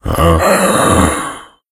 zombie_idle_10.ogg